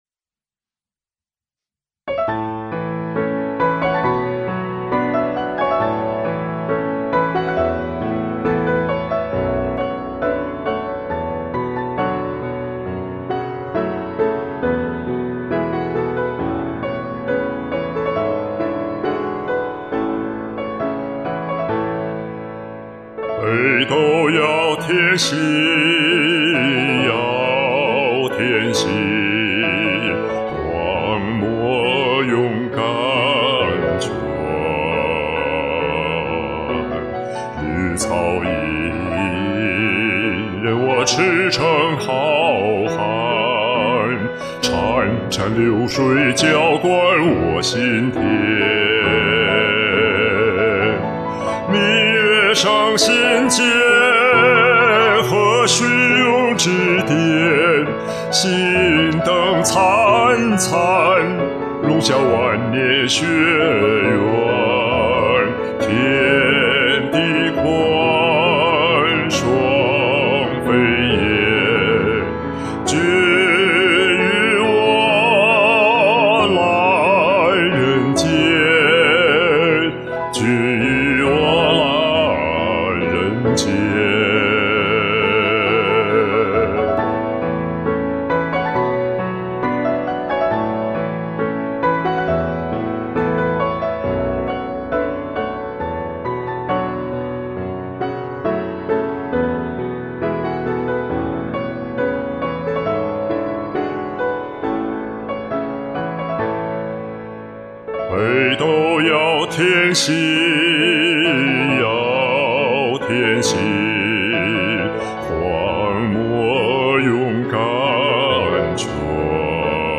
唱得厚重深情，音色美极了！
真是独特的嗓子💐💐